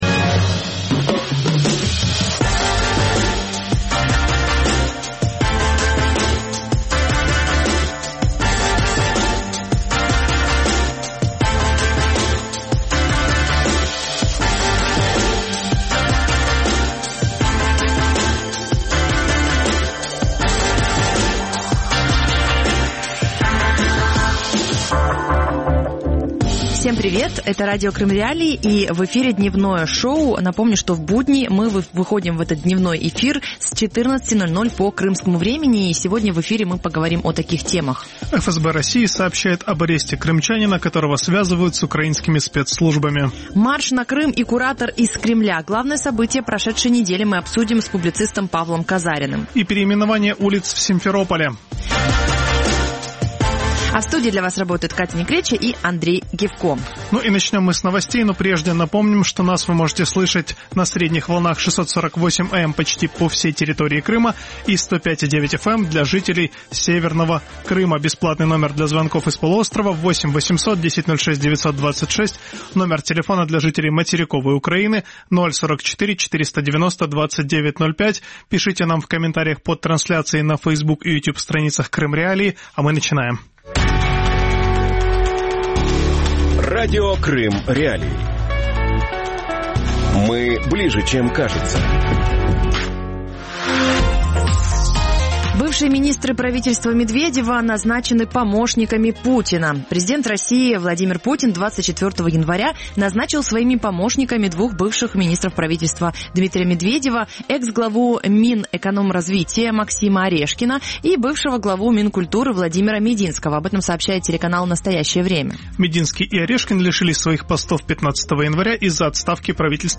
Марш на Крым и куратор из Кремля | Дневное ток-шоу